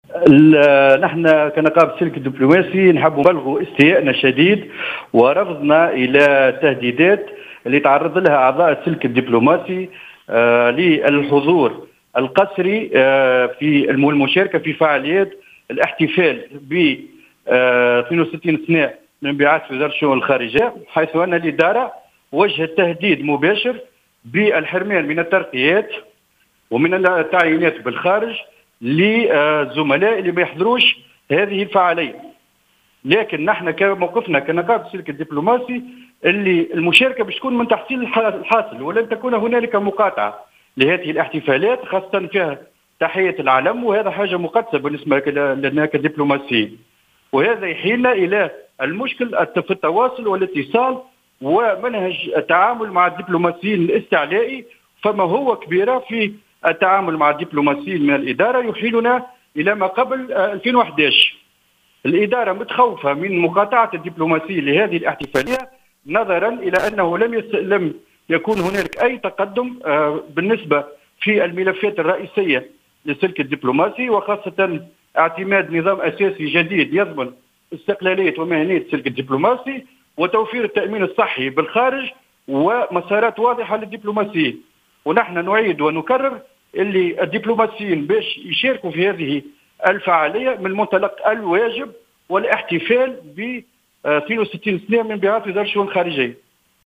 تصريح للجوهرة "اف ام"